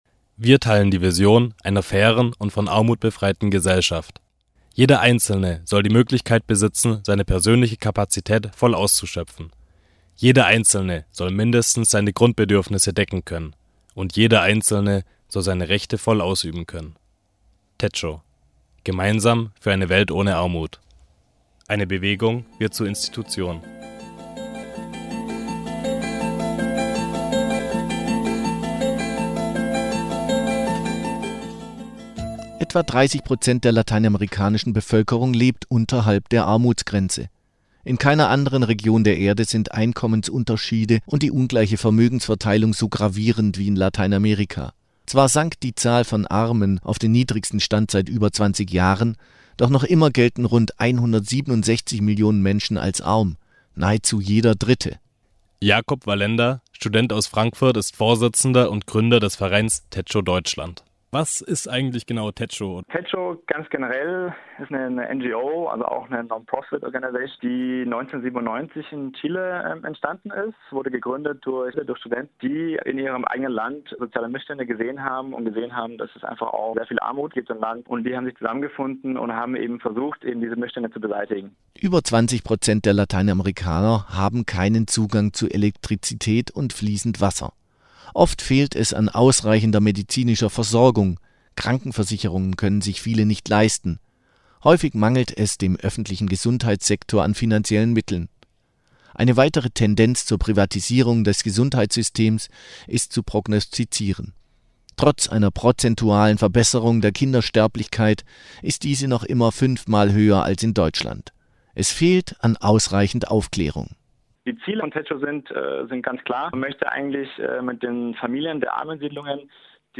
Beitrag: TECHO - Eine Bewegung wird Institution